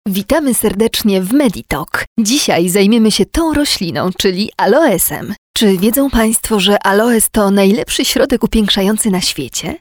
Sprecherin polnisch.
Sprechprobe: Industrie (Muttersprache):
polish female voice over artist